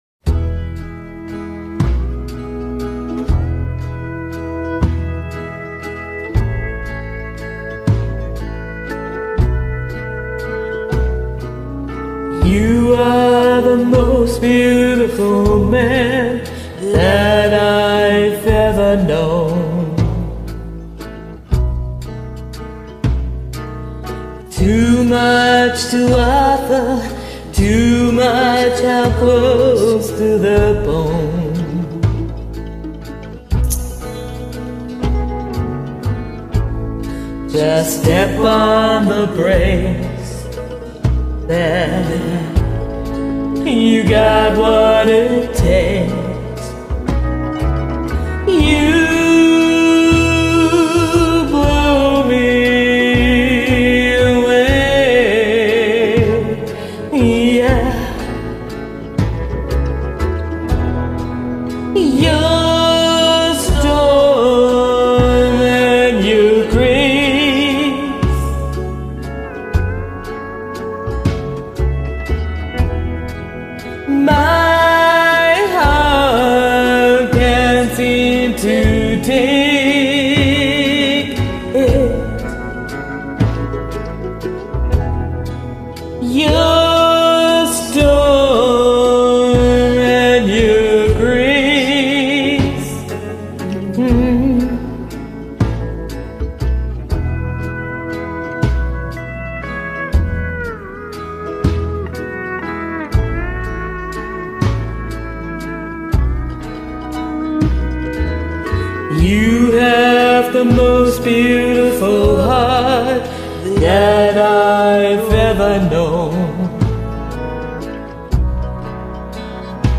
Blues & Rock